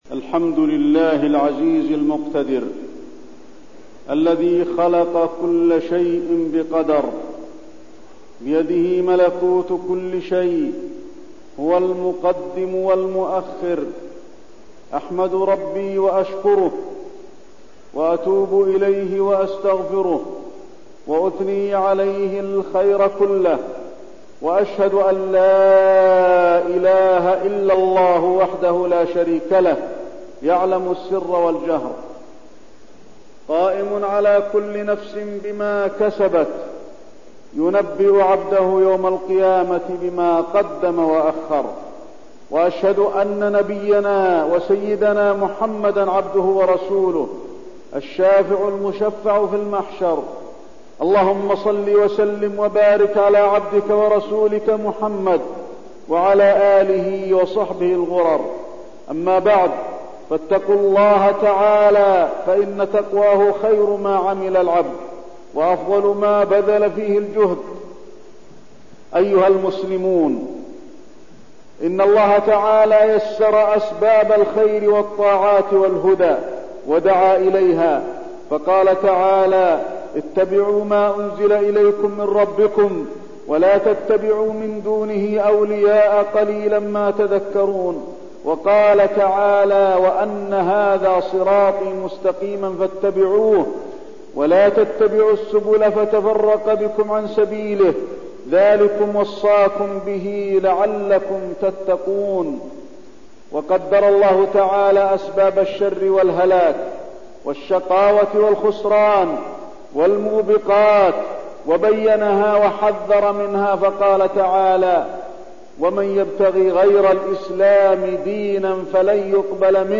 تاريخ النشر ٢٩ محرم ١٤١٢ هـ المكان: المسجد النبوي الشيخ: فضيلة الشيخ د. علي بن عبدالرحمن الحذيفي فضيلة الشيخ د. علي بن عبدالرحمن الحذيفي أضرار جليس السوء